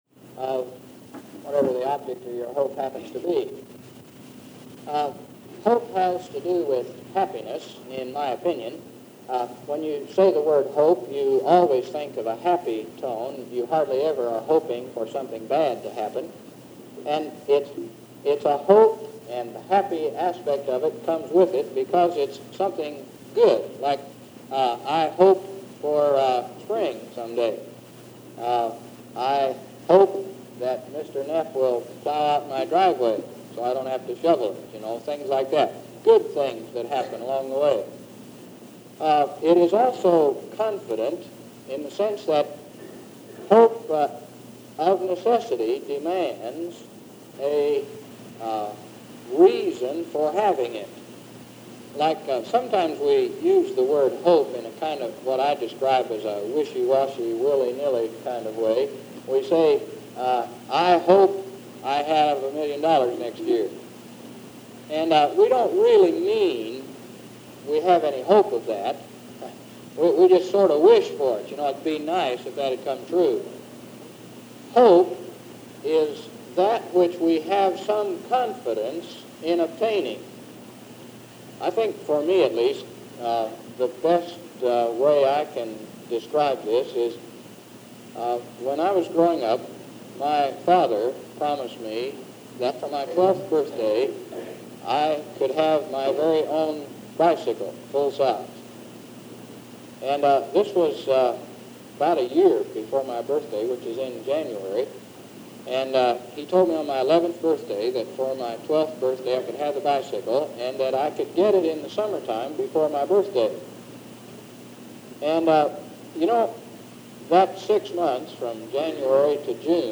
The audio quality of this cassette was rather poor, including some spots where it just dropped out. Those have been edited to eliminate long stretches of silence. But this sermon recording begins after it has already started and ends prematurely, due to those difficulties with the cassette.